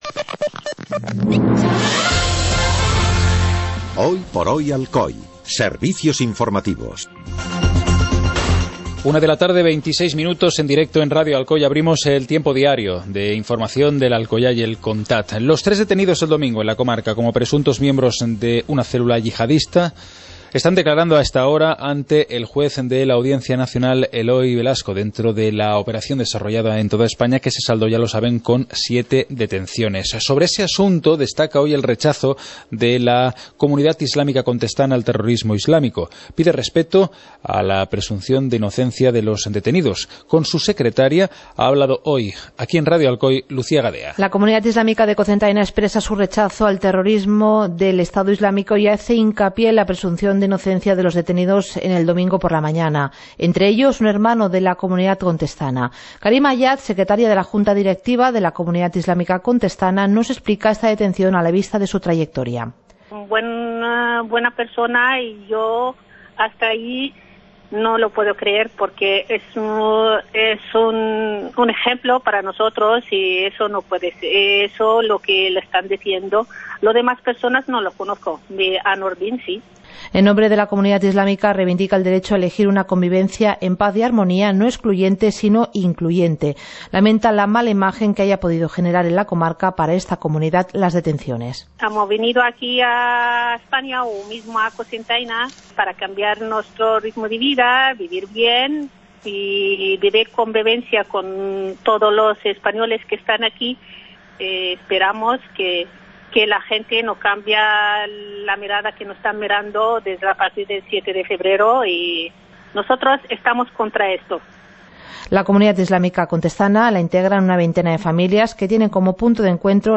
Informativo comarcal - martes, 09 de febrero de 2016